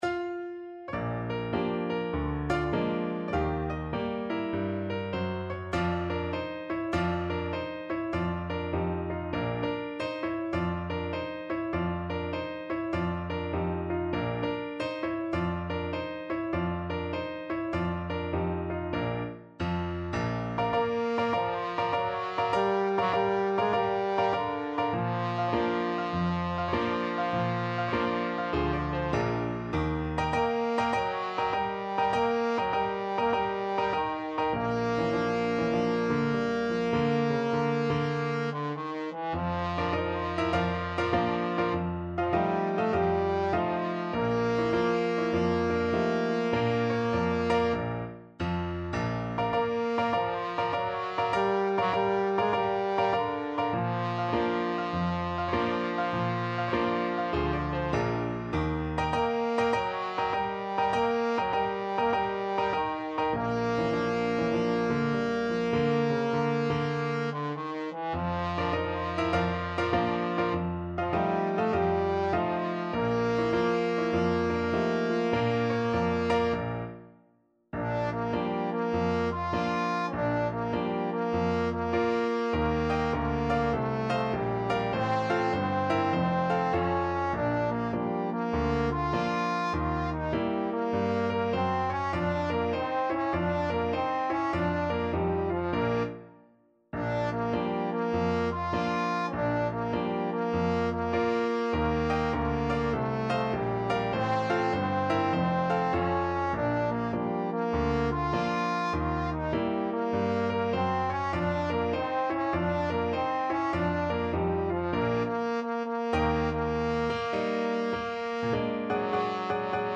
4/4 (View more 4/4 Music)
Jazz (View more Jazz Trombone Music)